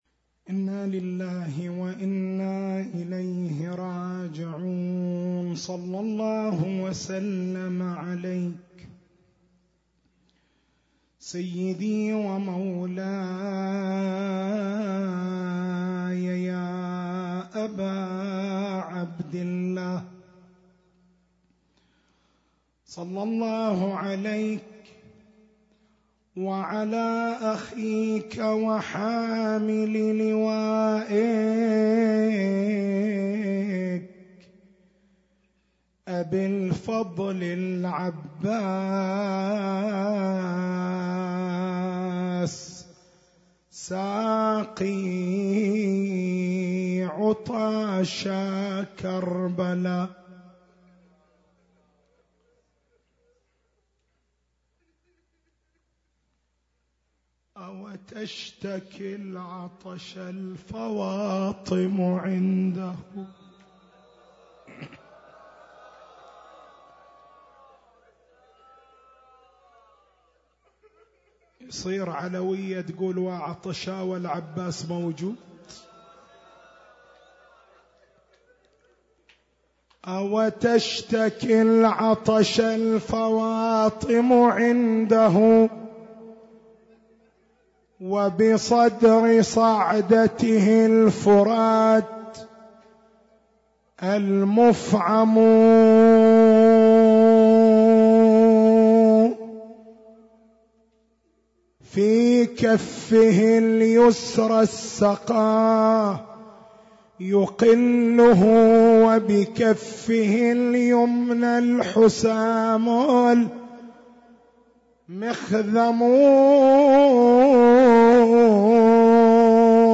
نصّ المحاضرة